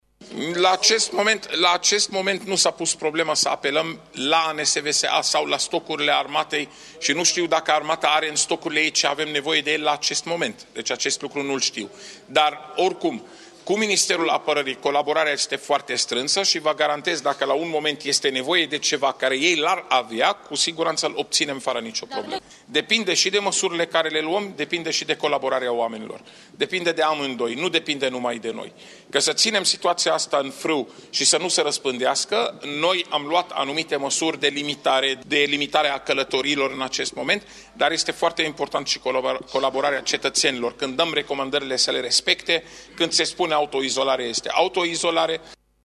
Secretarul de stat în Ministerul de Interne a declarat, după audierile din Comisia de sănătate a Camerei Deputaţilor pe problema coronavirusului, că trebuie introduse noi amendamente la proiectul actului normativ privind stocurile de urgenţă medicală şi instituirea carantinei.